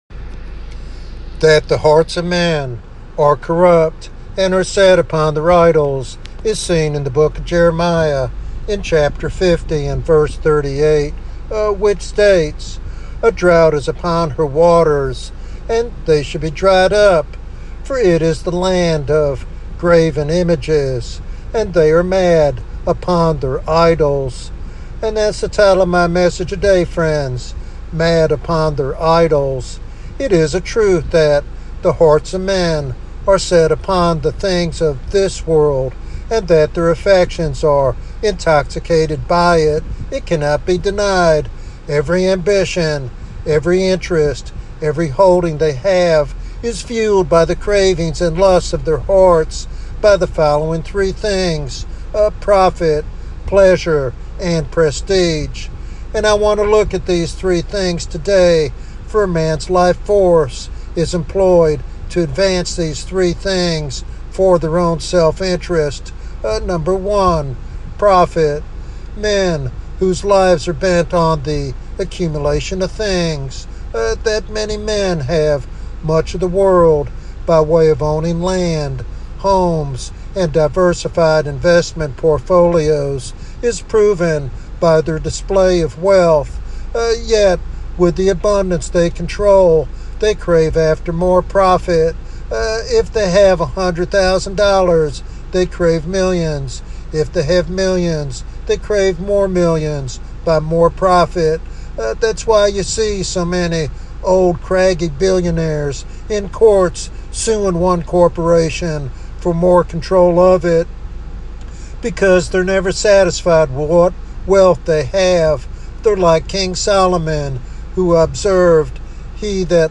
This topical sermon challenges believers to confront their idols and seek genuine transformation.